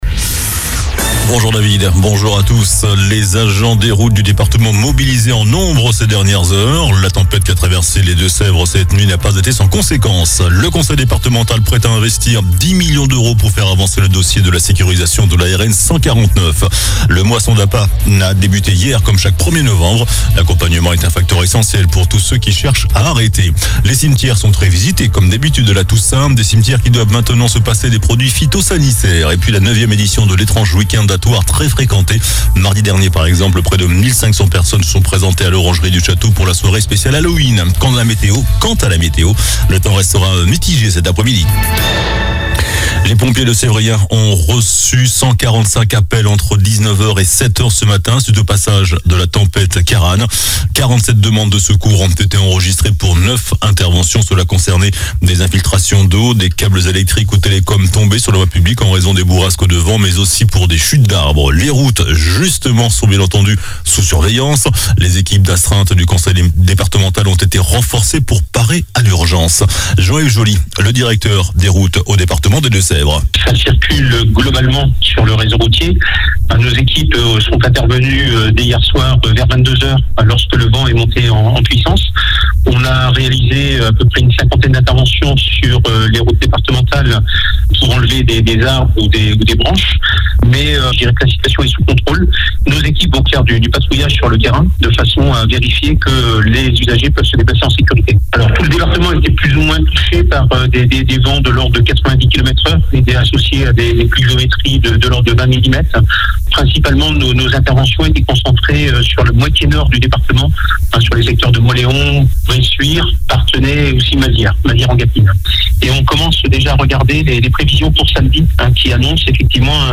JOURNAL DU JEUDI 02 NOVEMBRE ( MIDI )